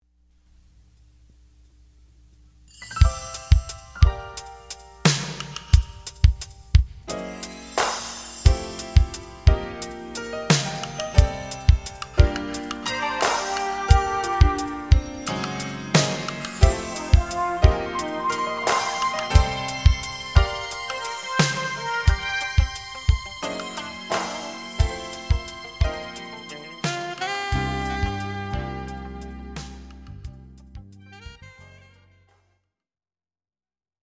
Full band and drums
Bass
Alto Saxophone